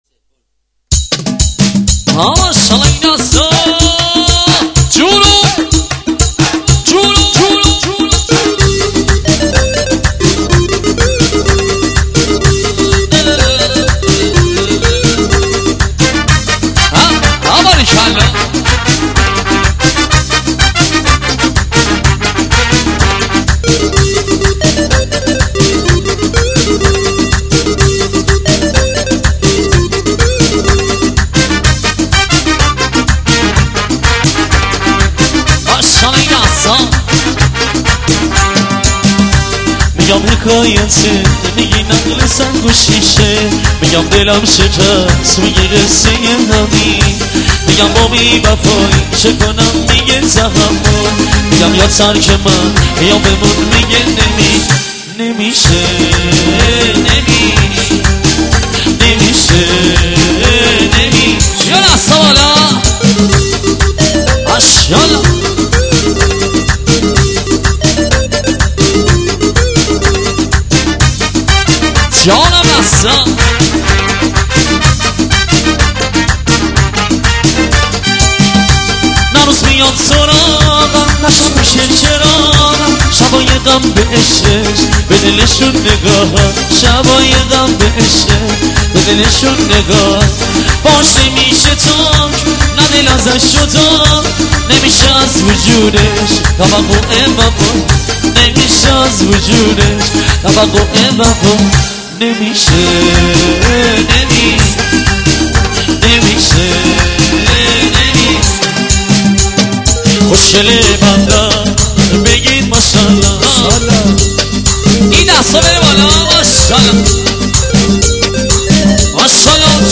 آهنگ عروسی شاد تالار عروسی ارکستی ایرانی